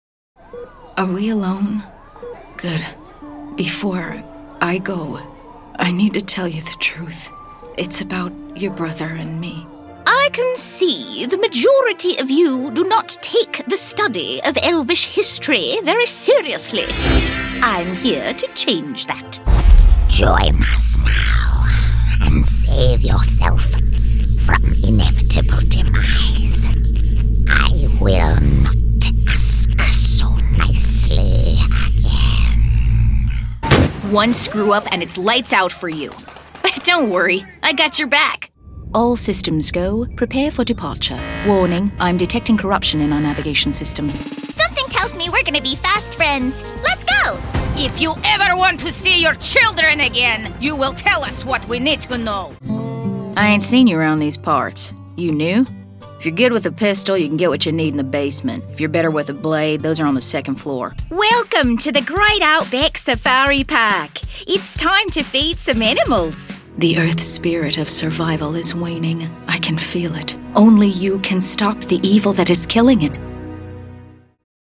English (American)
Video Games
Broadcast quality home studio.
HighMezzo-SopranoSopranoVery High
FriendlyConversationalConfidentEducatedArticulateDynamicCorporateExperiencedRelatableBubblyBrightApproachableUpbeat